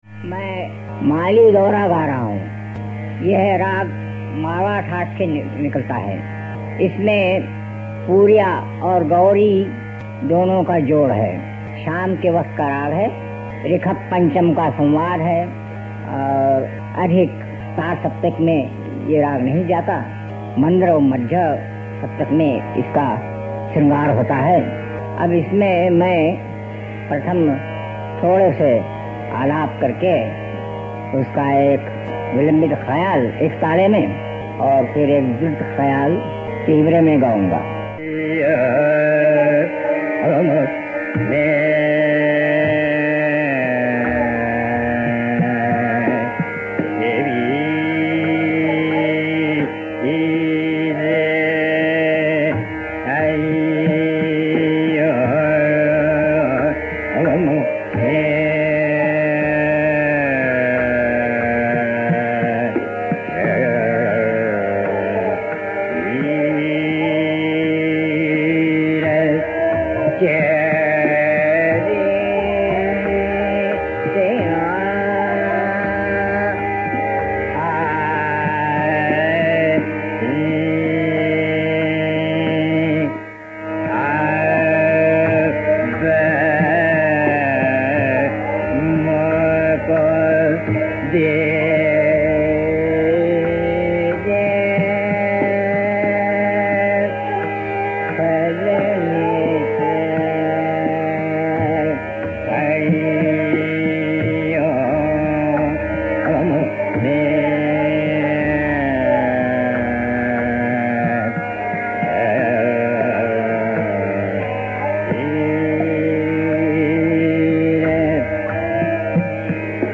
The komal dhaivat is seen in Shree-laced phrases such as S, r N’ d P.  The shuddha dhaivat is deployed in Pooriya-like gestures.  This performance of a traditional khayal (documented by Bhatkhande) begins with a brief introduction to the raga.